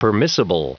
Prononciation du mot permissible en anglais (fichier audio)
Prononciation du mot : permissible